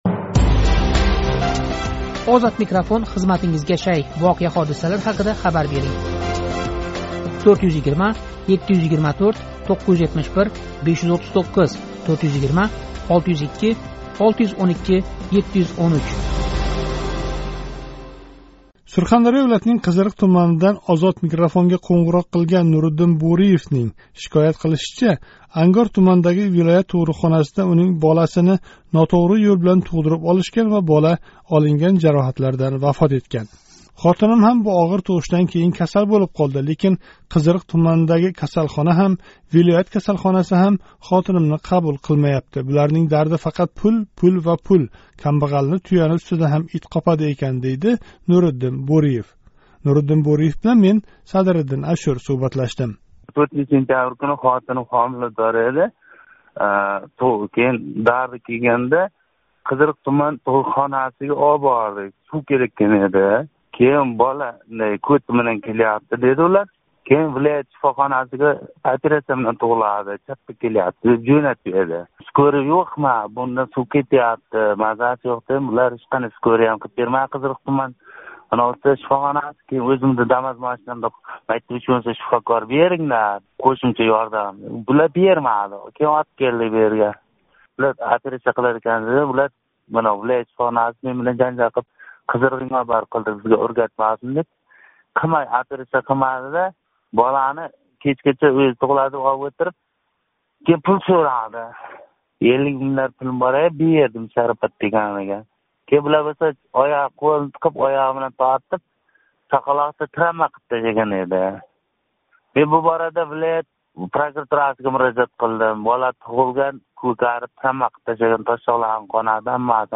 Сурхондарё вилоятининг Қизириқ туманидан OzodMikrofonга қўнғироқ қилган